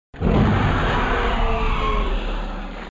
Dinosaur Roar Or Scream 4 Sound Button - Free Download & Play
Animal Sounds Soundboard144 views